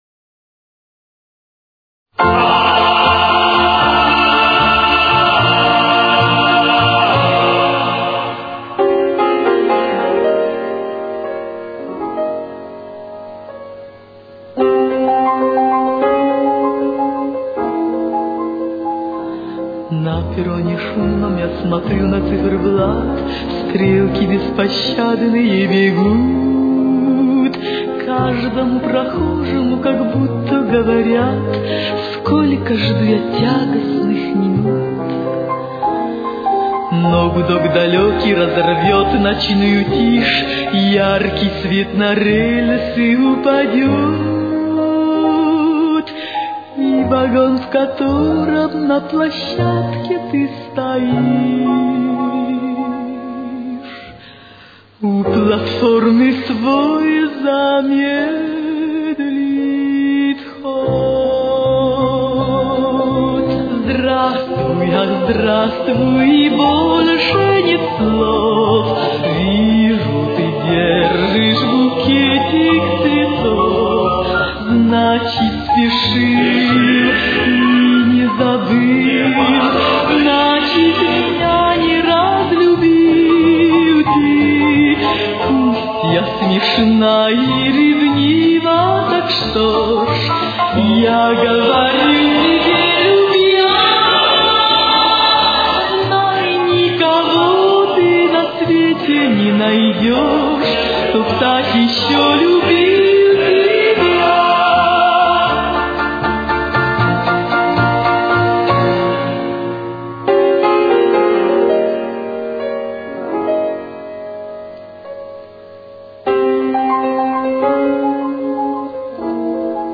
Темп: 90.